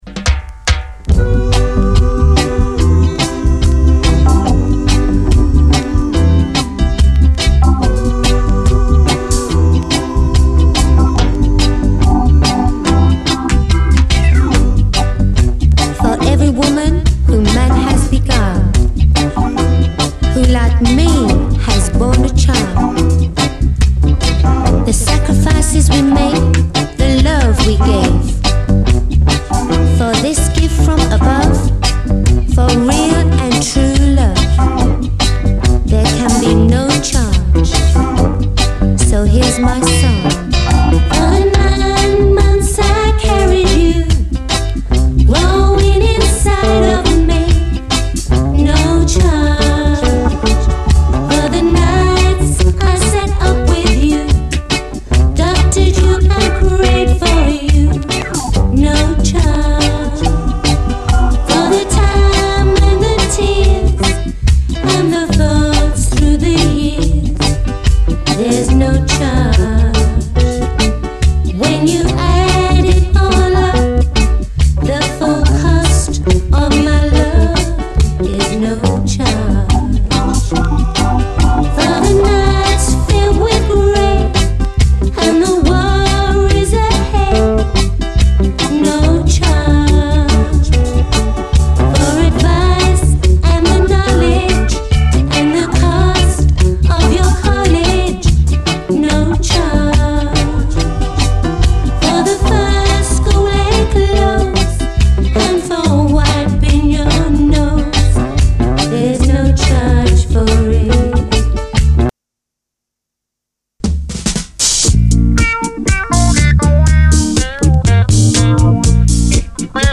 REGGAE, 7INCH